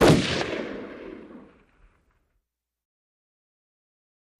Automatic Weapon 3, Single & Multiple Bursts, Echoey.